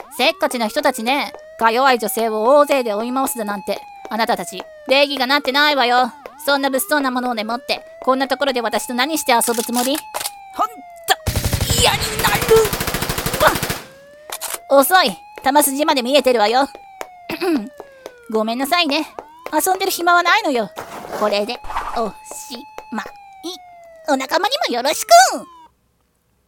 1人声劇『逃走劇』